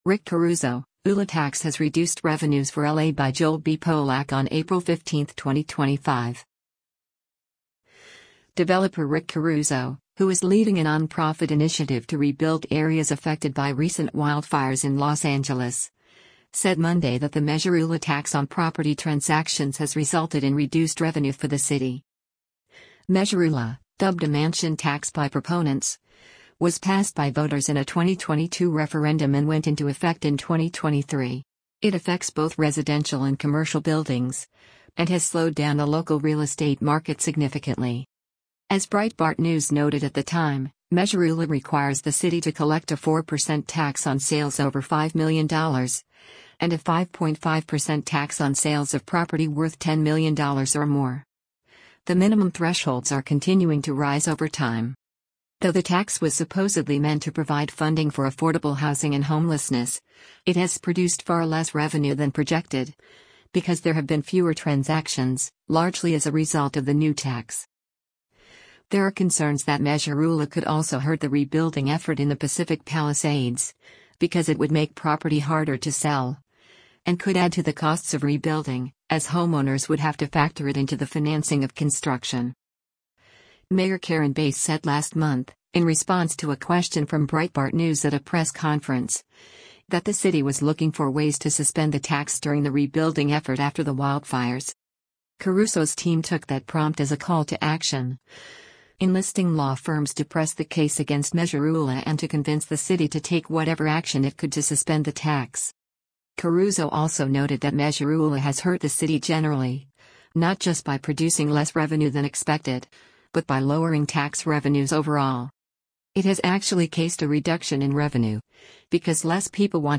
Caruso told a seminar for residents of Pacific Palisades